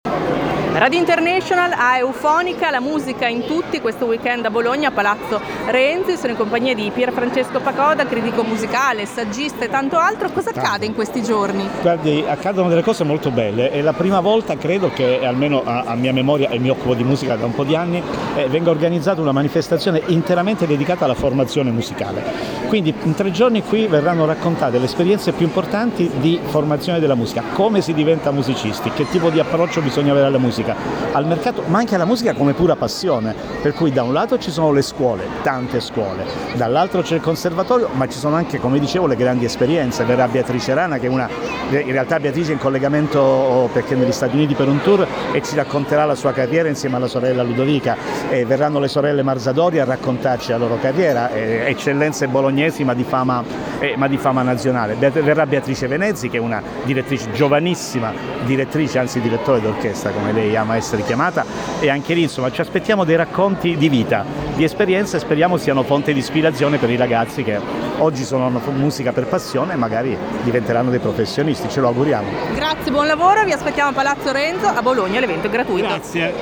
Critico Musicale